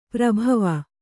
♪ prabhava